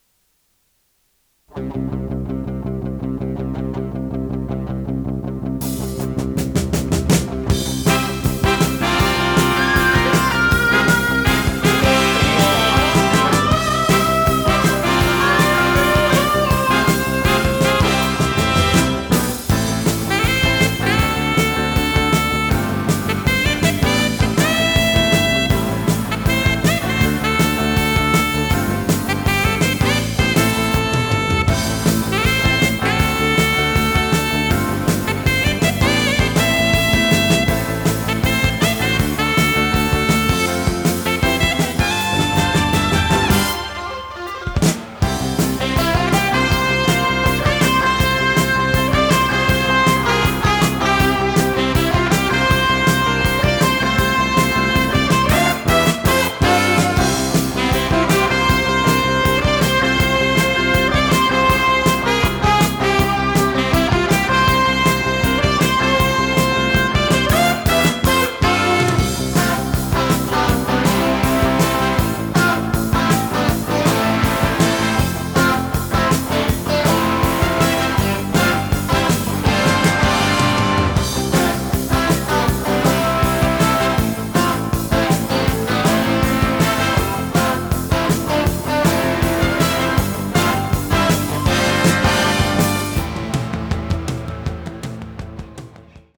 テープ：RTM
ノイズリダクションOFF
【フュージョン・ロック】96kHz-24bit 容量52.8MB